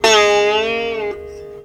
SITAR LINE55.wav